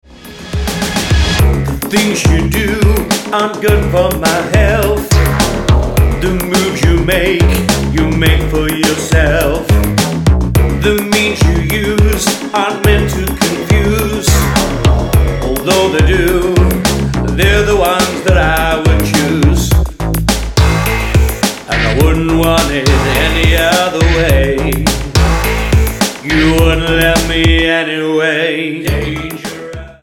--> MP3 Demo abspielen...
Tonart:C#m Multifile (kein Sofortdownload.